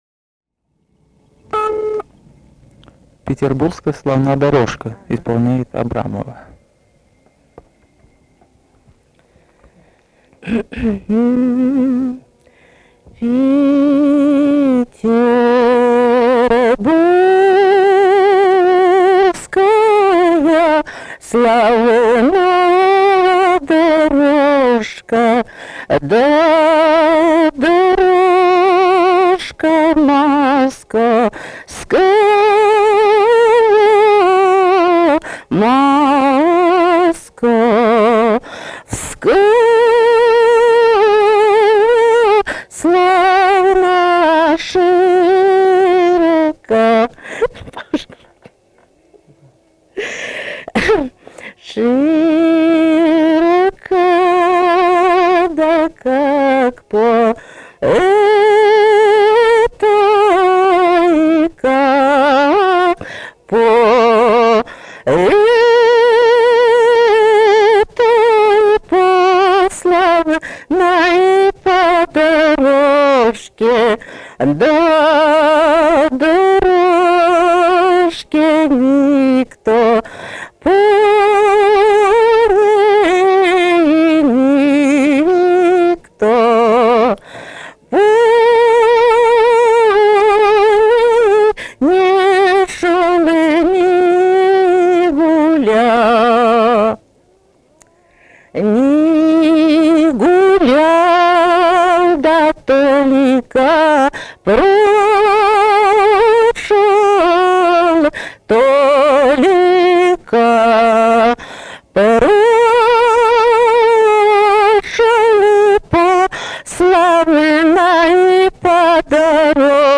Русские народные песни Владимирской области 17. Петербургская славна дорожка (протяжная рекрутская) с. Мстёра Вязниковского района Владимирской области.